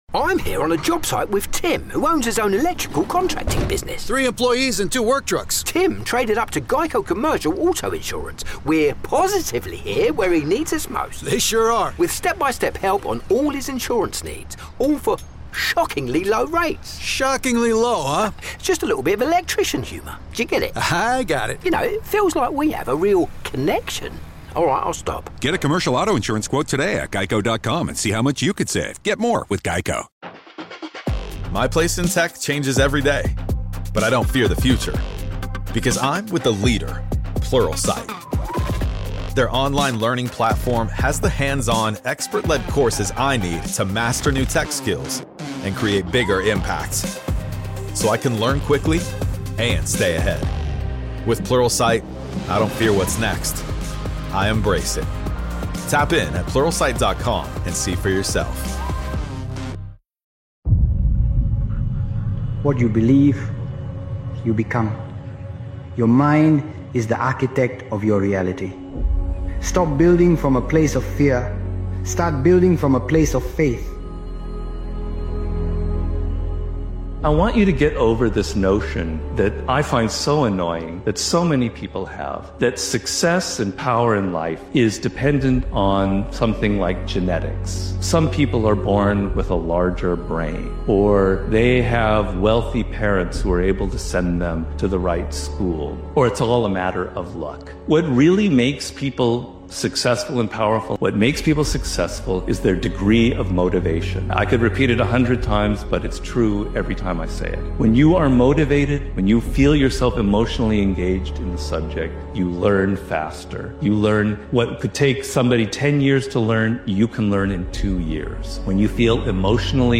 Powerful Motivational Speeches Video is an uplifting and confidence-fueling motivational video created and edited by Daily Motivations.